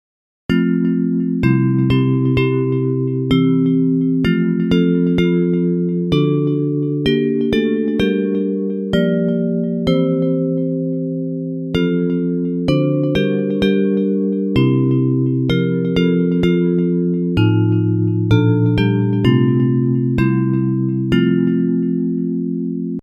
Bells Version
Music by: USA campmeeting melody;